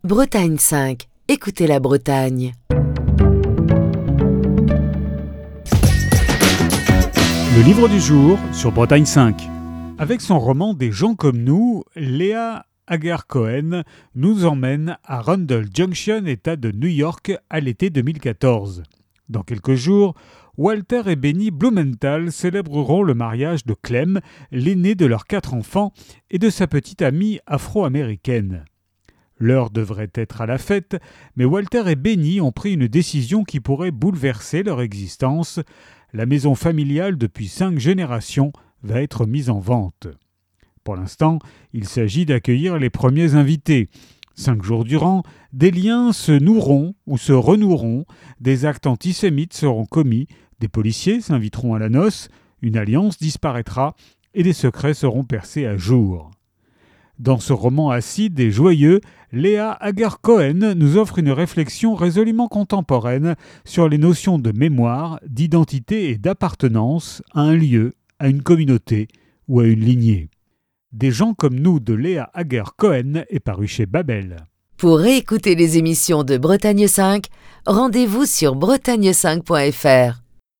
Chronique du 19 juin 2025.